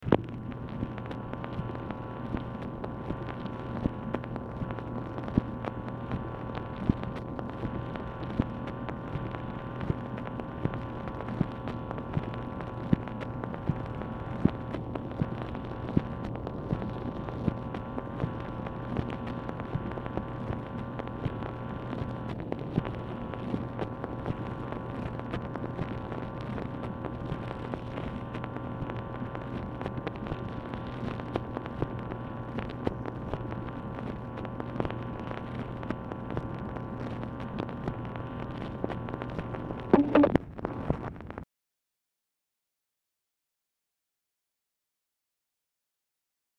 MACHINE NOISE
LBJ Ranch, near Stonewall, Texas
Telephone conversation
Dictation belt